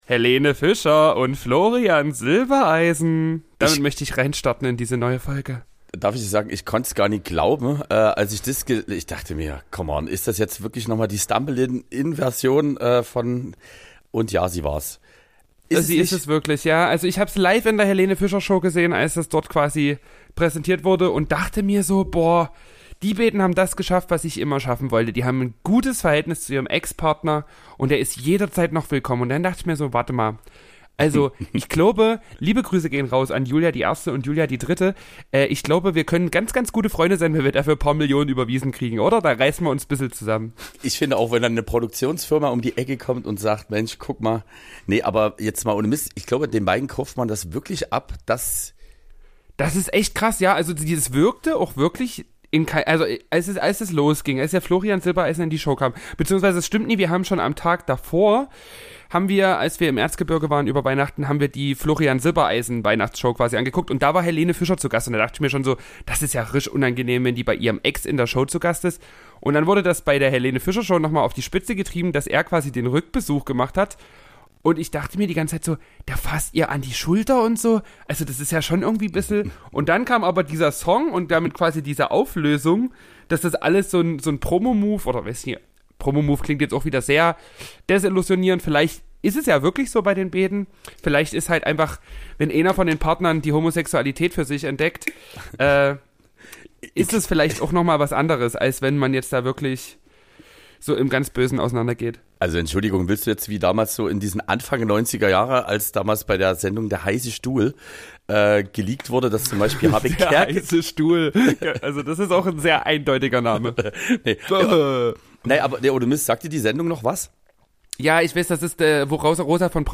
Die zwei stärksten Lebern der ostdeutschen DJ-Szene reden über alles, außer über Gott und die Welt. Dresdens Nachtleben wird mit spitzer Zunge zerrissen und es wird gelallt und gepöbelt, was das Zeug hält.